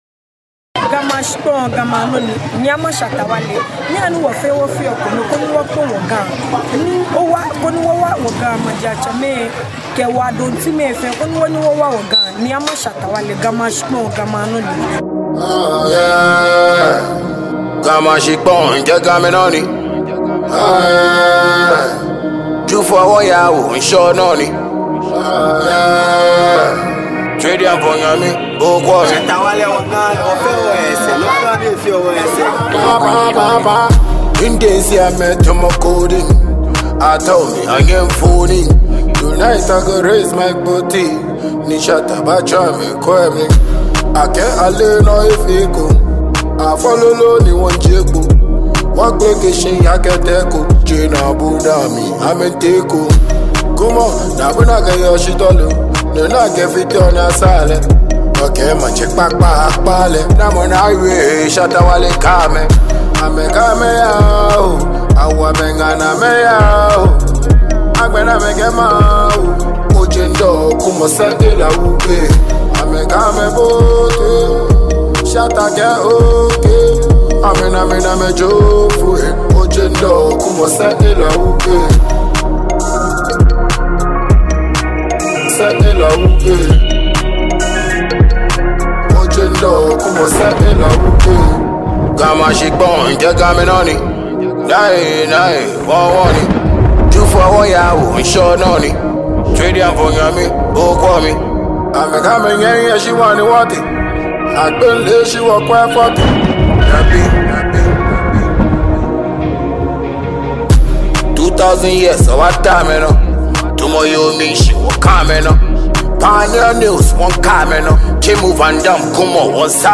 Ghanaian dancehall King